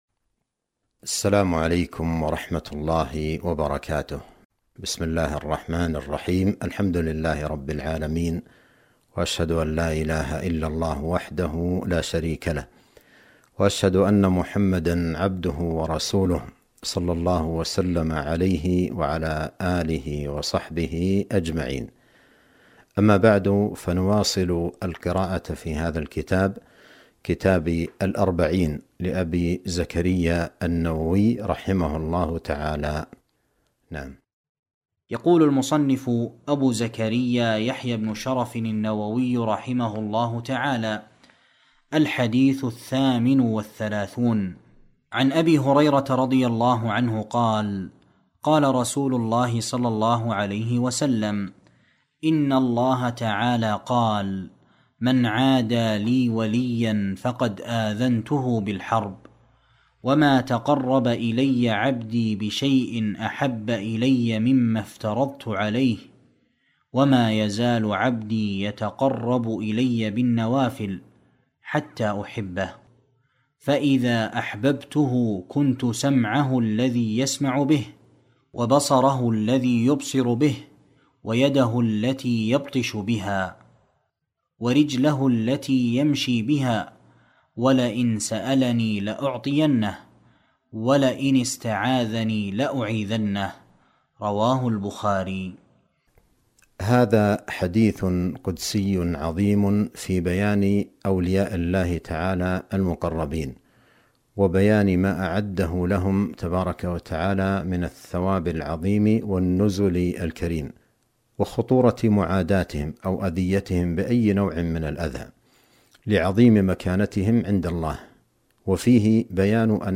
درس في قناة السنة النبوية بالمدينة النبوية